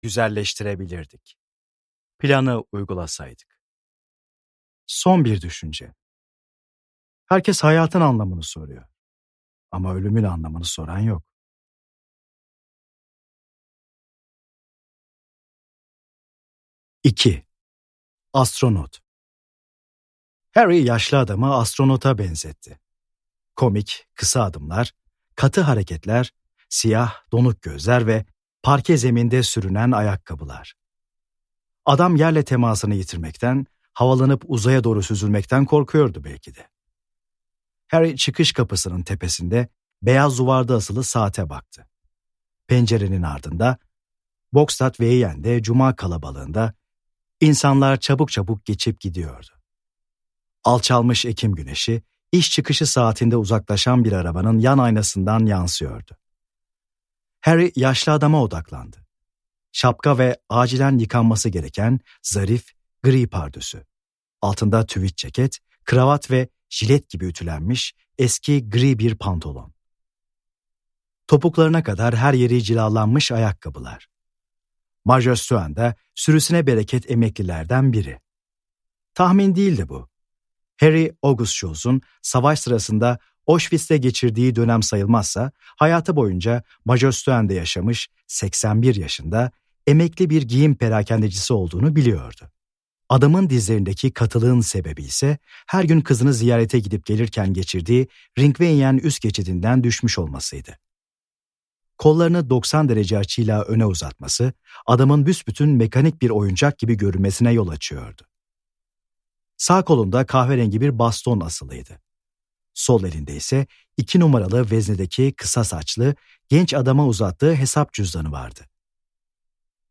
Nemesis - Seslenen Kitap